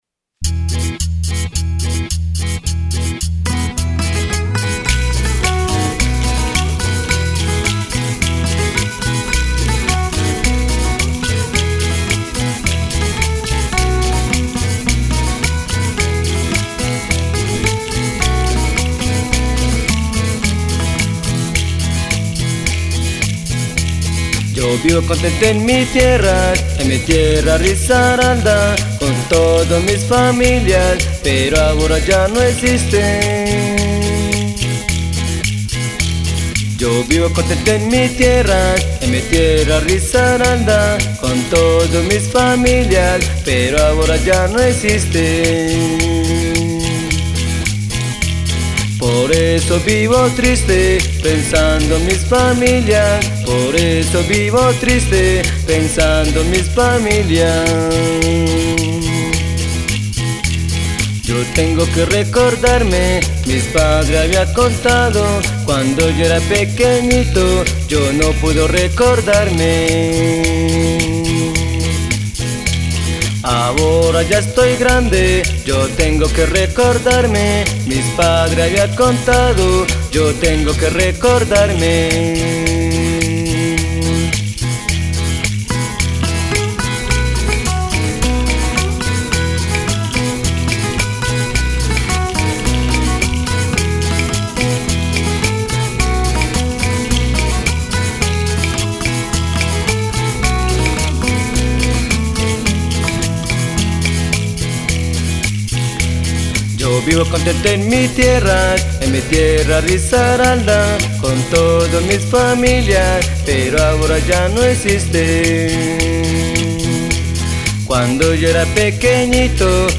Canción
voz y guitarra.
bajo y puntero.
voz y percusión.